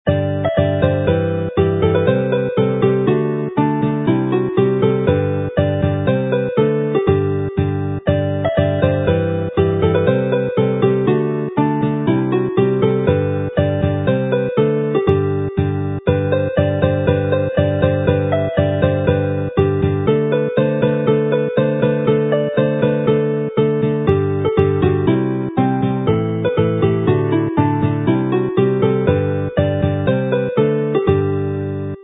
mp3 file as a march, fast with chords